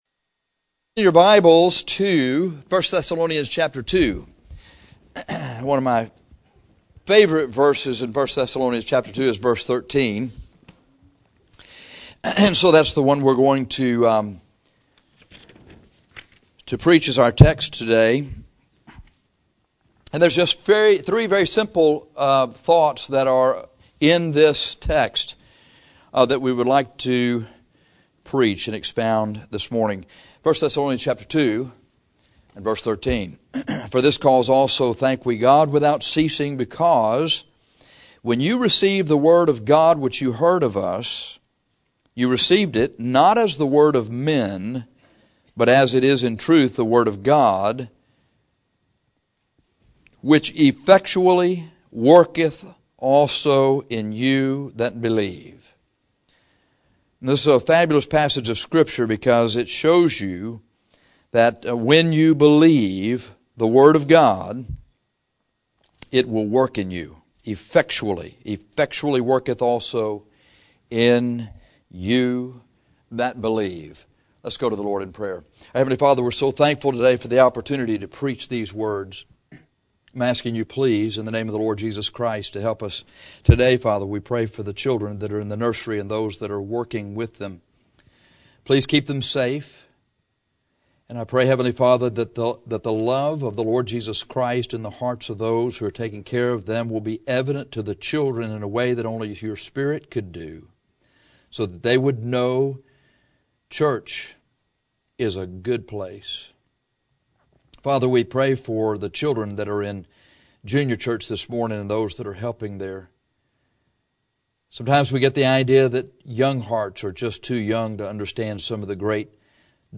1 Thes 2:13 is one of the most profound verses on the word of God in the Bible. This is an excellent sermon on how it works.